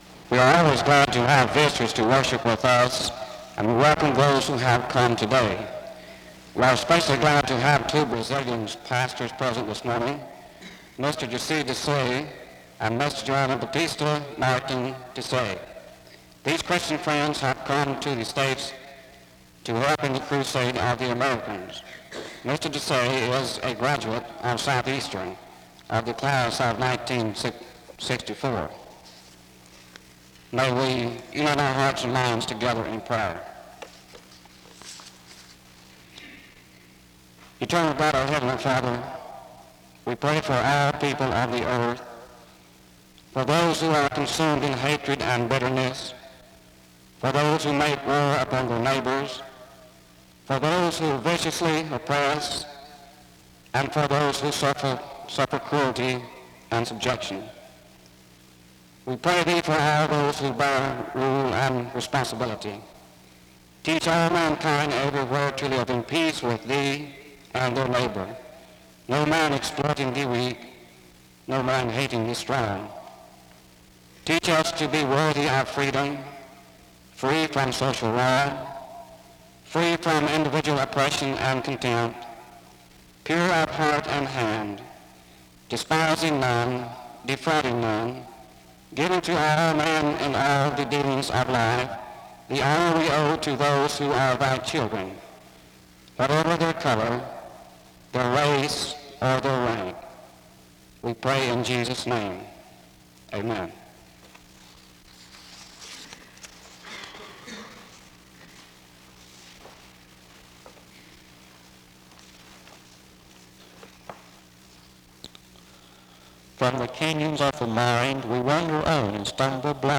SEBTS_Chapel_Student_Service_1969-04-11.wav